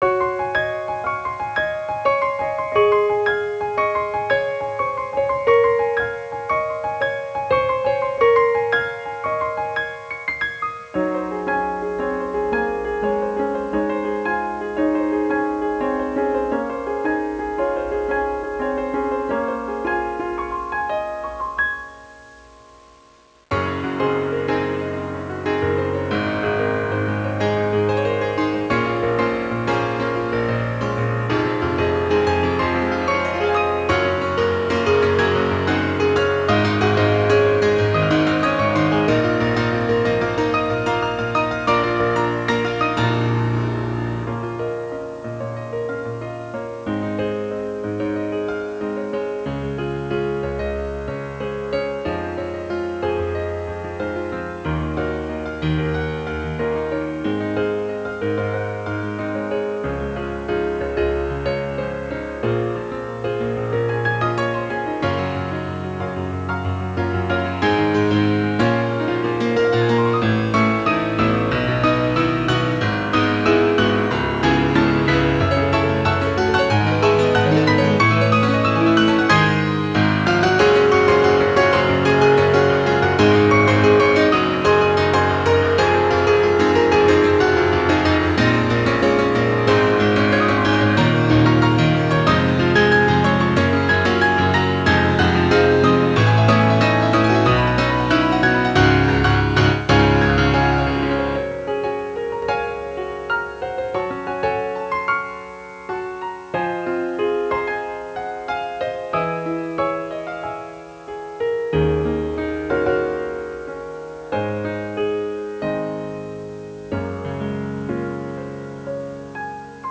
applause.wav